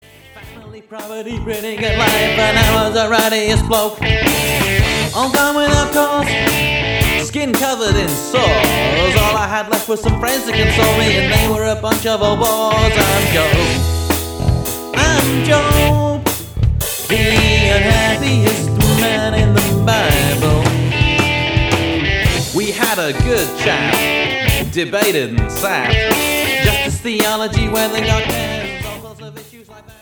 A rocking and whimsical collection of songs